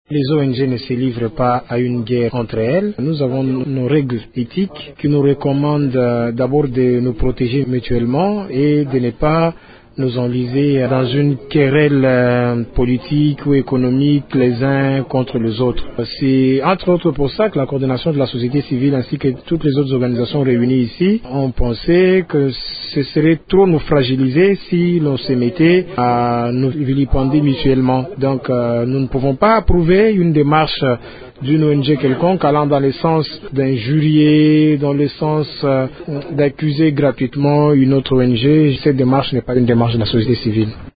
Au cours d’une conférence de presse tenue dimanche 2 mai à Lubumbashi, les responsables de la société civile ont déclaré:
L’un des orateurs s’exprime :